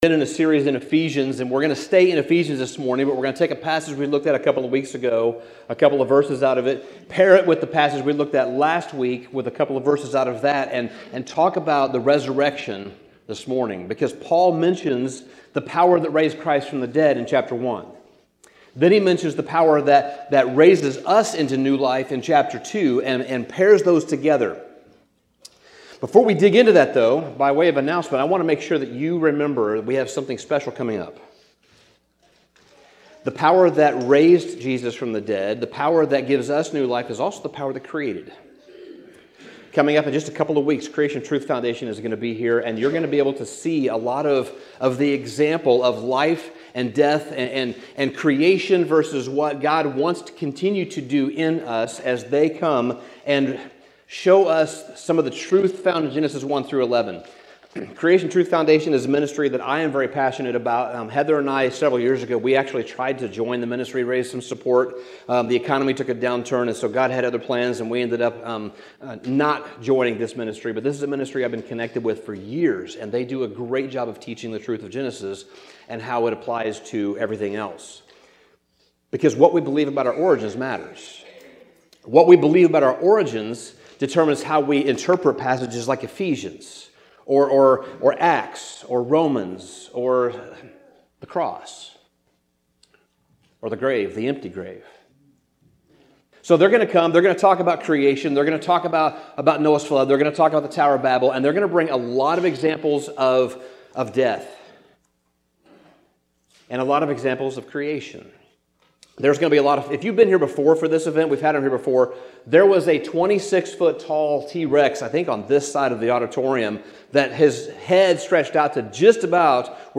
Sermon Summary Easter is not only the celebration of Christ’s resurrection — it is the renewal of faith through the power that raised him from the dead. Paul declares that this same power is now at work in believers, lifting us from spiritual death to new life and seating us with Christ in the heavenly realms.